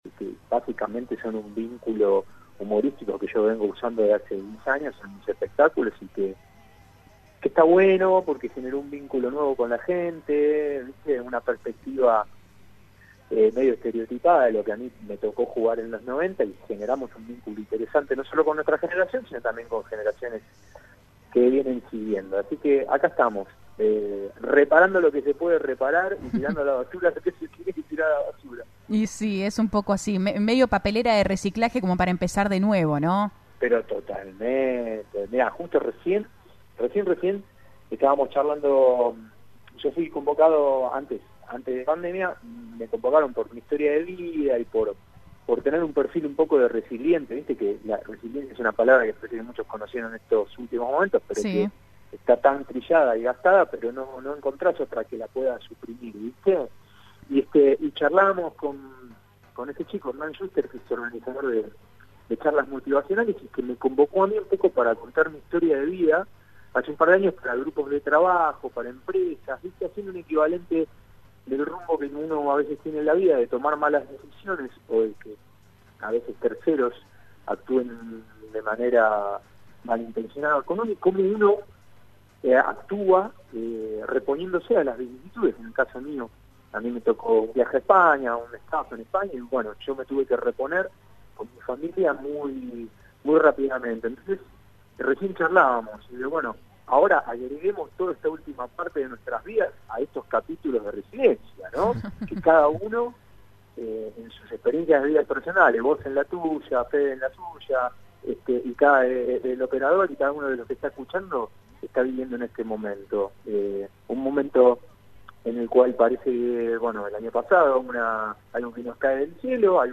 El cantante habló en La Tarde de la 100 y dijo que a algunos jurados «se los come el personaje».